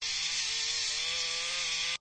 robot_move.ogg